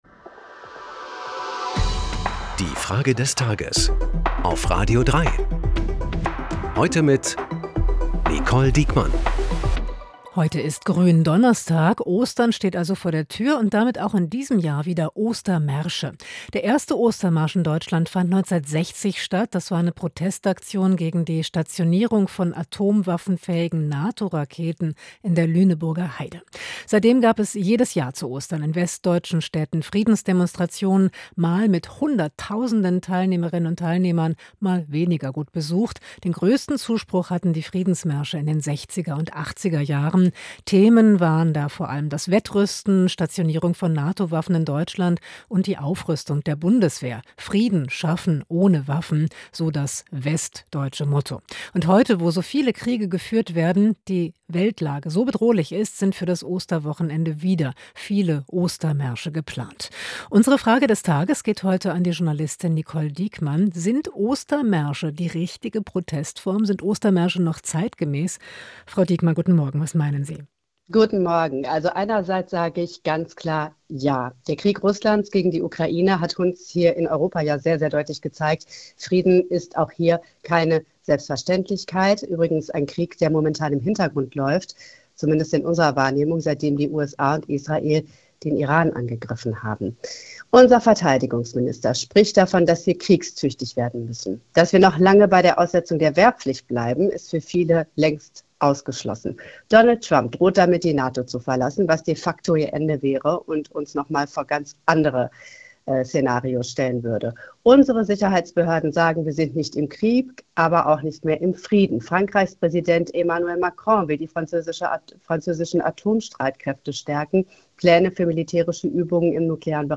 Zehn starke Stimmen im Wechsel "Die Frage des Tages" – montags bis freitags, immer um 8 Uhr 10.
Wir fragen unsere Kommentatorin